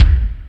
Lotsa Kicks(10).wav